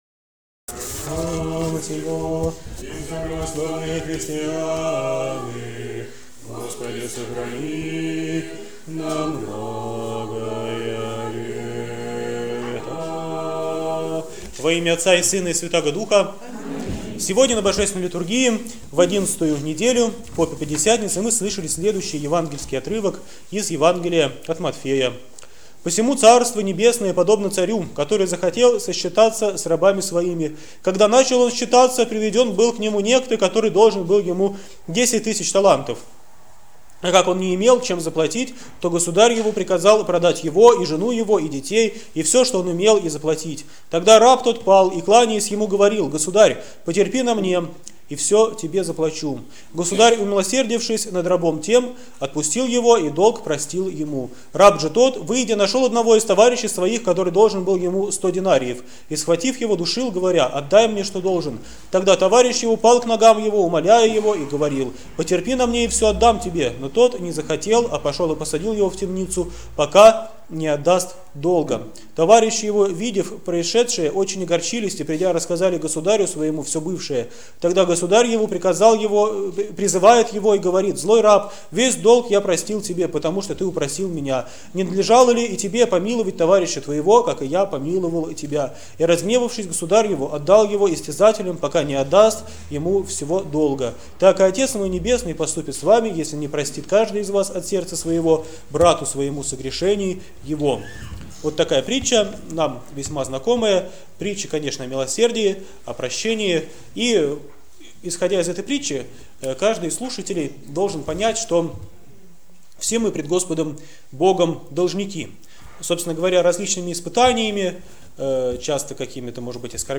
ПРОПОВЕДЬ В НЕДЕЛЮ 11-Ю ПО ПЯТИДЕСЯТНИЦЕ 2014